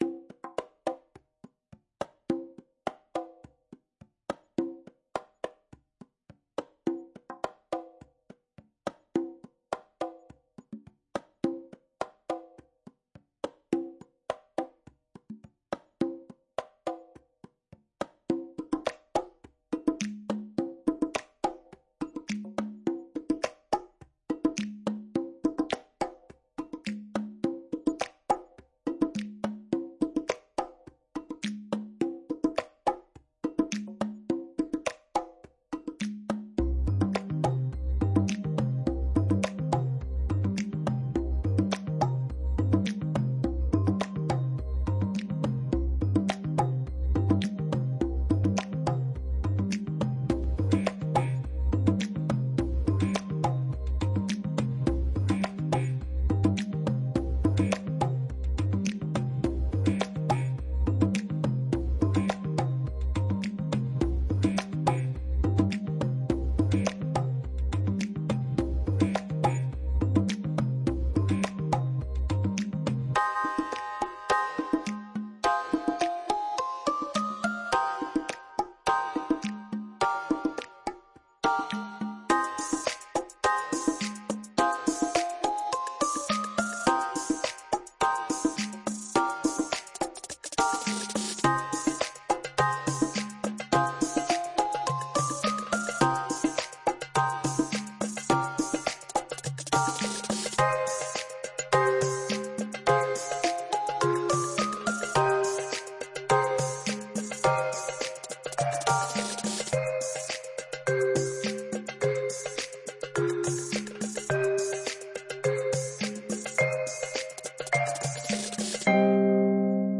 Création sonore pour le spectacle rupture des maux